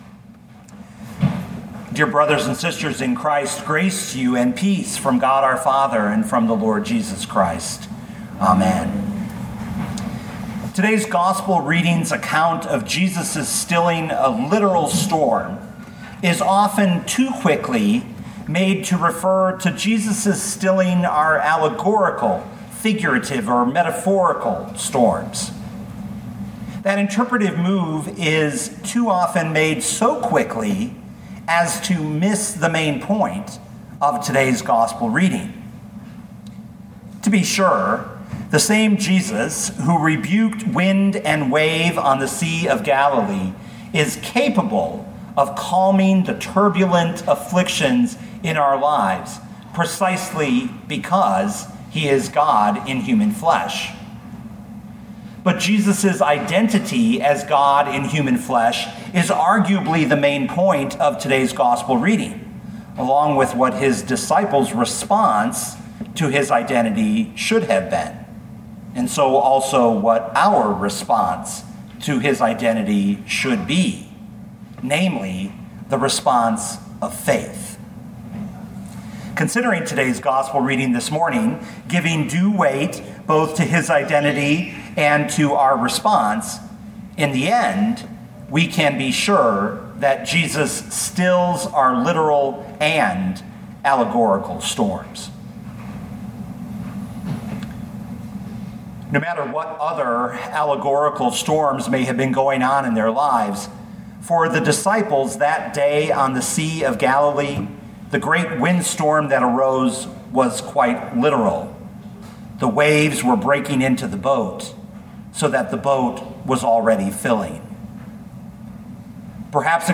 2021 Mark 4:35-41 Listen to the sermon with the player below, or, download the audio.